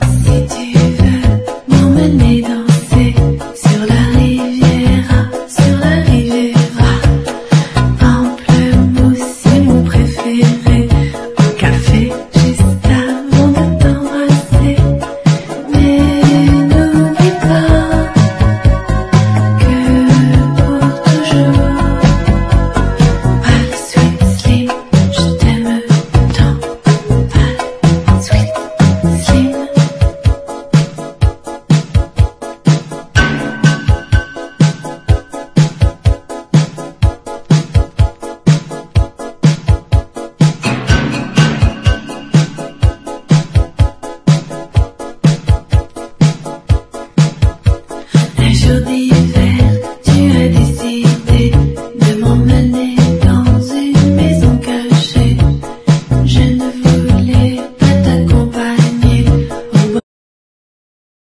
¥1,080 (税込) ROCK / 80'S/NEW WAVE.
POWER POP / PUNK